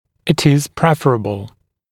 [ɪt ɪz ‘prefrəbl][ит из ‘прэфрэбл]предпочтительно